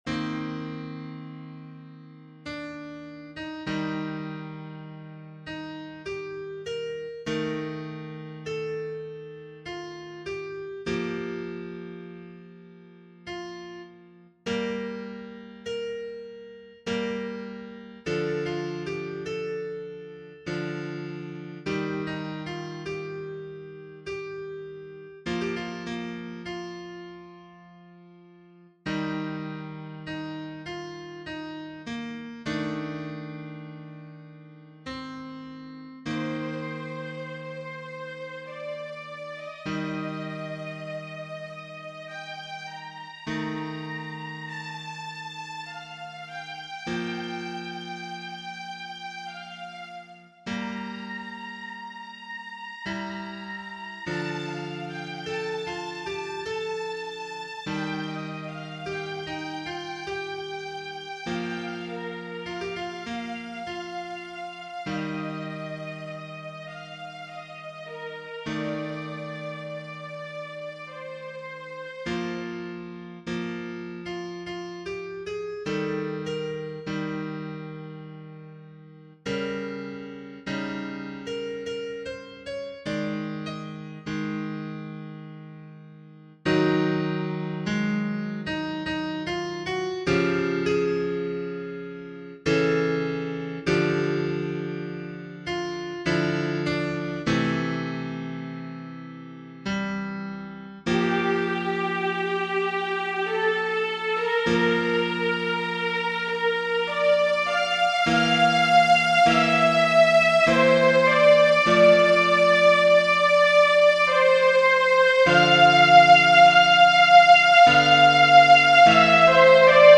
Genere: Opera